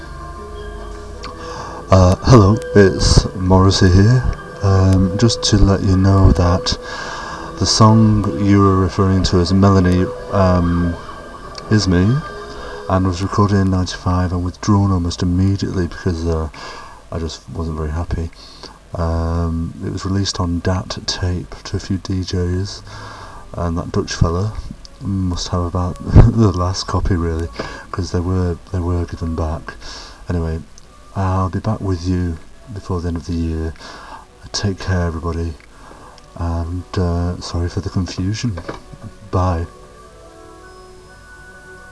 this sound file (900k .wav file), of a Morrissey impersonator telling people that the "Melanie" song is real and was sent on DAT to some DJs. It really does sound like him, but I have confirmed it is fake and have even heard that Morrissey himself finds it humorous.
fakemorrissey.wav